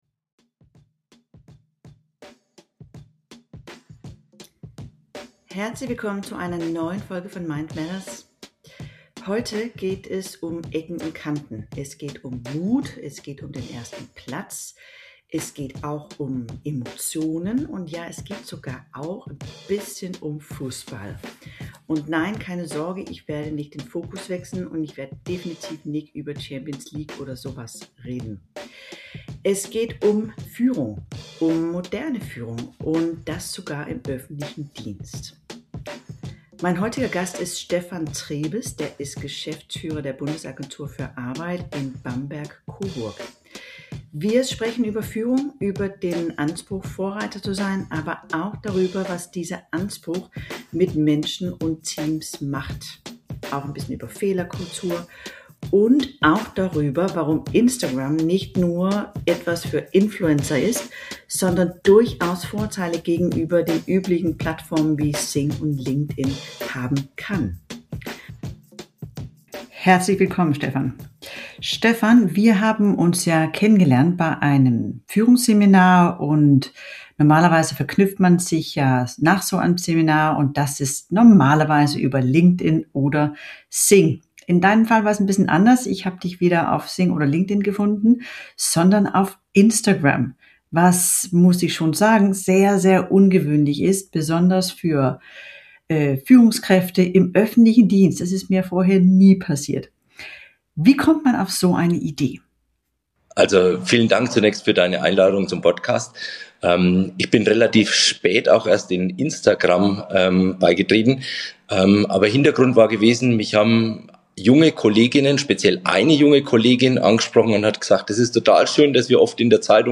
Raus aus der Reihe – ein Gespräch über Führung, Verantwortung und den Mut, anders zu sein.